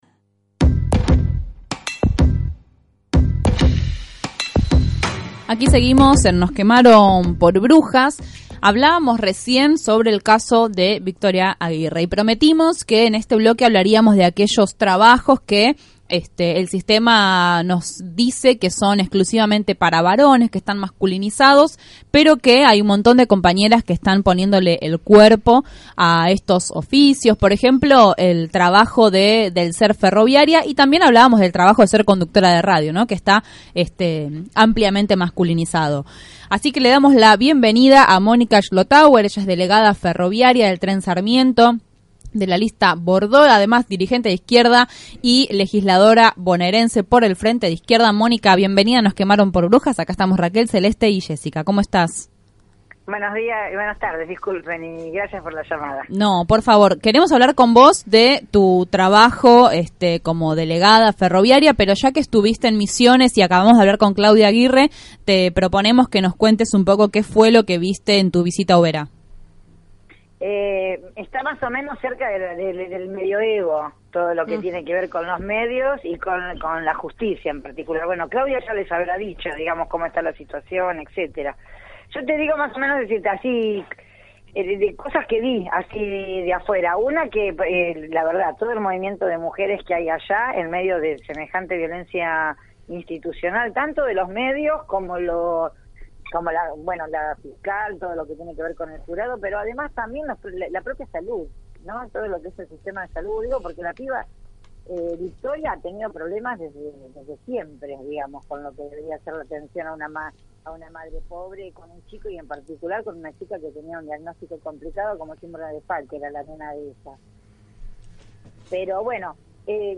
Aguerrida y segura, en esta entrevista cuenta cómo fue el proceso de organiación de las mujeres ferroviarias del tren Sarmiento, y las luchas que están dando.